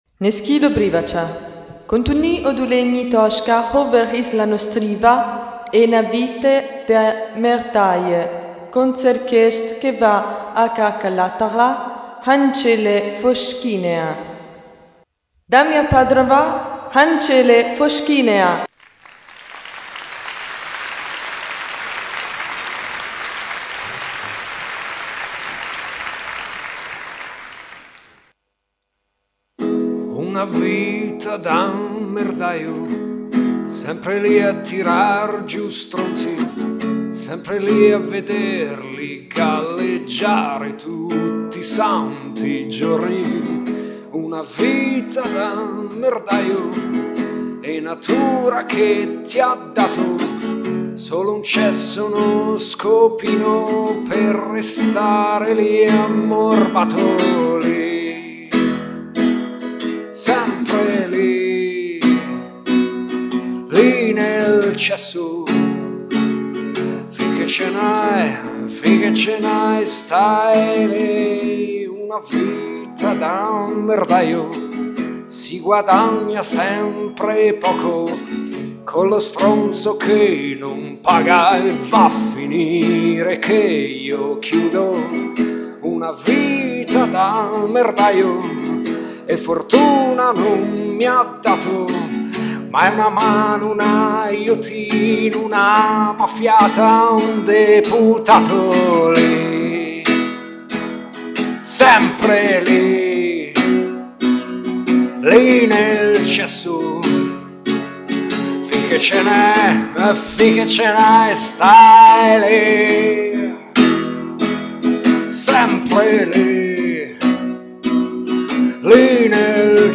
Live acoustic jam session in mp3!!!